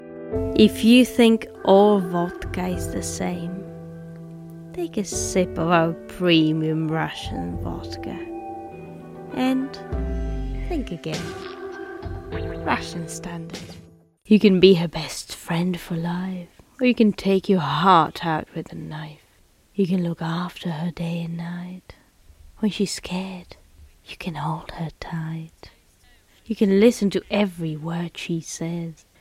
Czech, Eastern European, Slovak, Female, Home Studio, 20s-20s